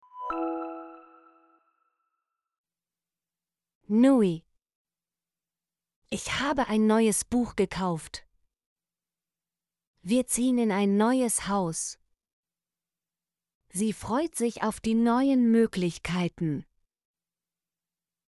neue - Example Sentences & Pronunciation, German Frequency List